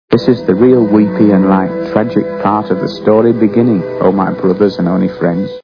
A Clockwork Orange Movie Sound Bites